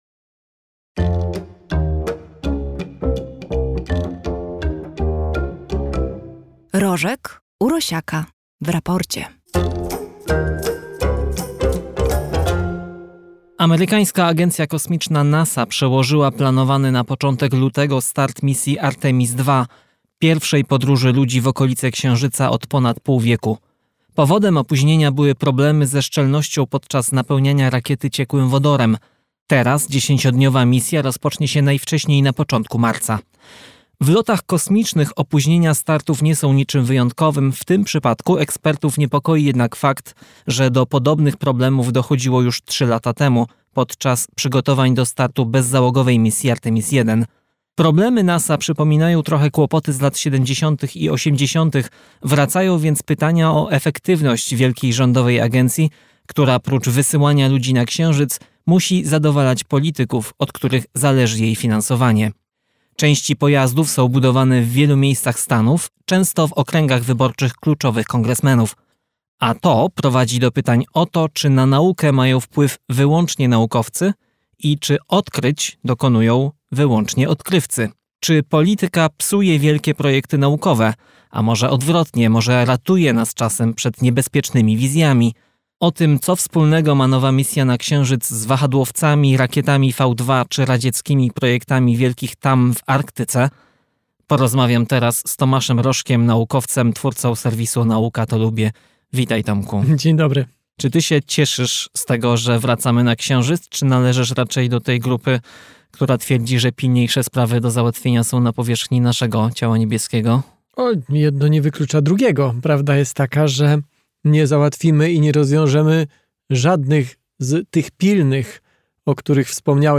W najnowszym odcinku „Raportu” Tomasz Rożek rozmawia z ekipą podcastu Raport o stanie świata o opóźnionej misji Artemis 2 i o tym, dlaczego problemy techniczne NASA przypominają te sprzed dekad.